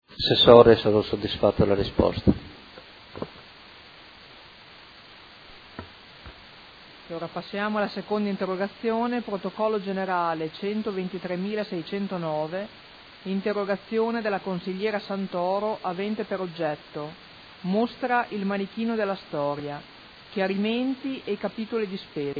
Seduta del 5/11/2015. Interrogazione del Consigliere Cugusi (SEL) avente per oggetto: Bagni pubblici stazione.